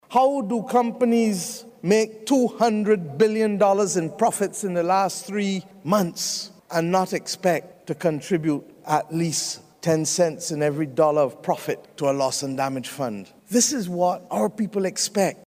Barbados’ Prime Minister Mia Amor Mottley addressing world leaders at the Climate Implementation Summit in Egypt.